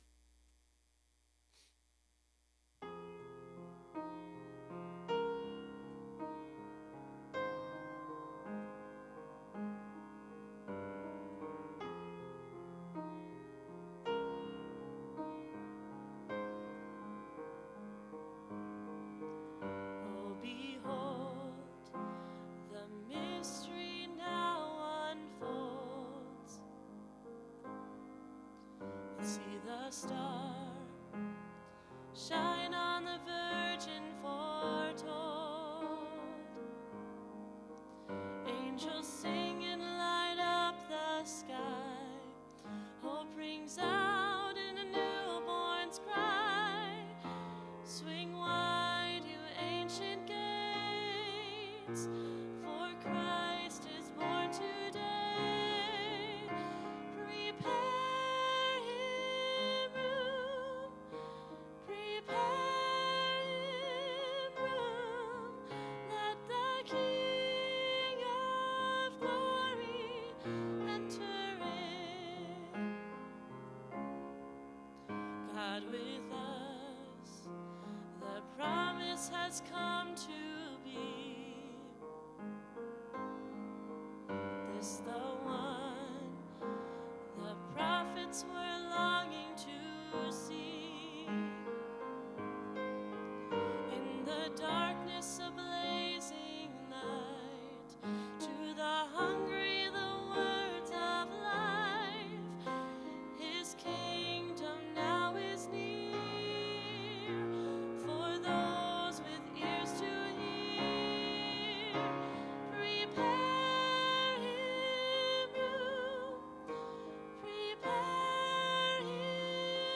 Click here for practice track